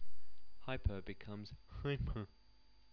For consonants other than 'm', 'n' and 'ng' the soft palate must be closed otherwise the sound of the consonant takes on the 'nasal' quality
and is termed hypernasal.
hypern~1.wav